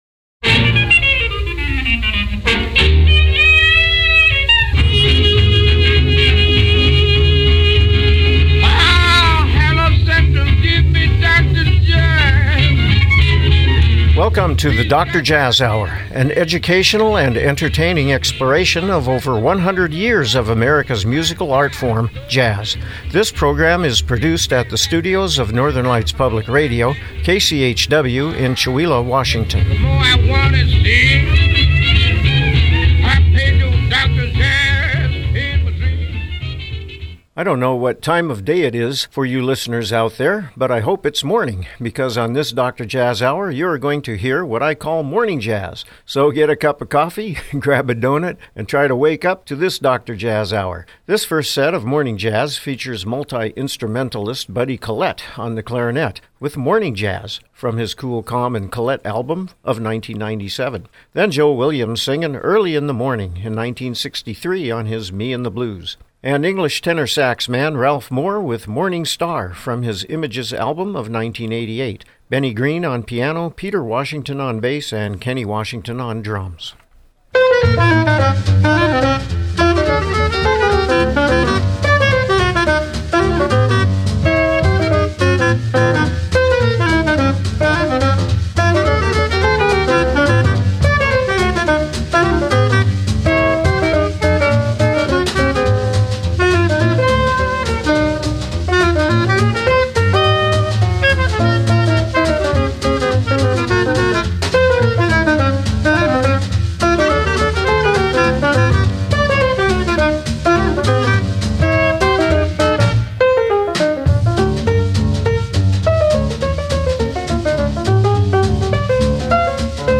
Program Type: Music